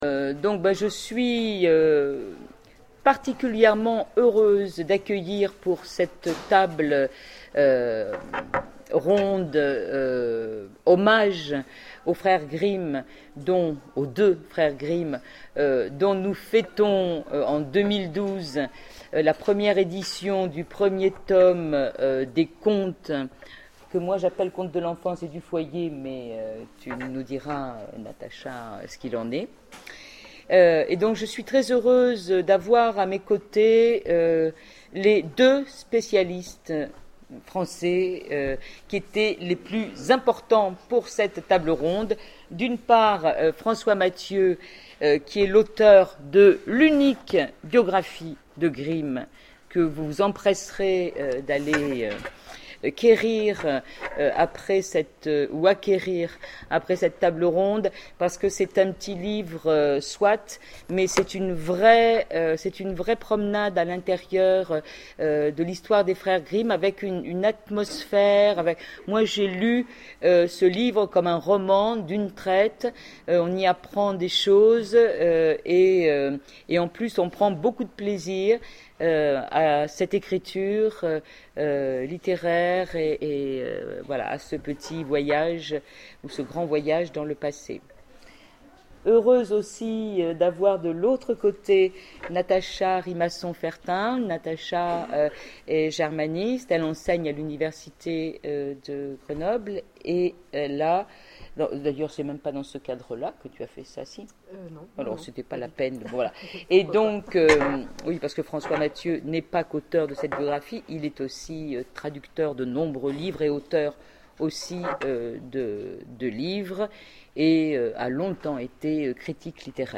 Imaginales 2012 : Conférence Les Contes de Grimm